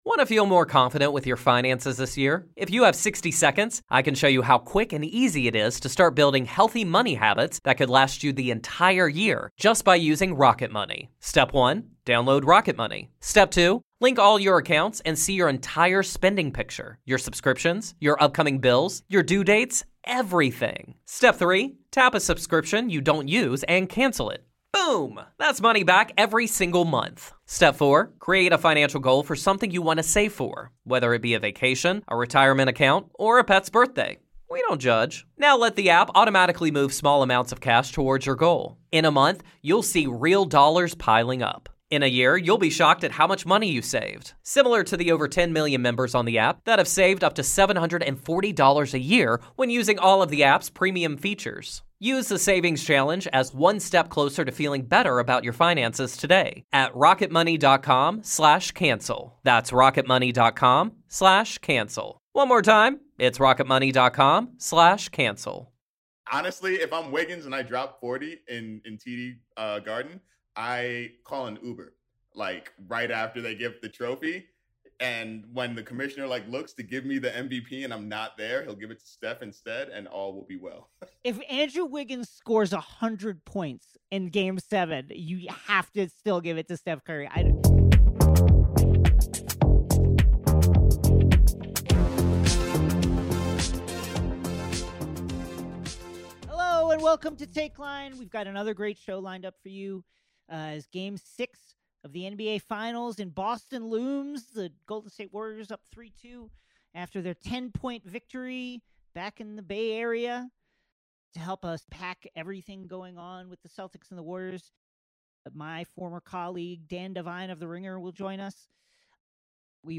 give their knee jerk reactions to game five in a conversation recorded moments after the final whistle.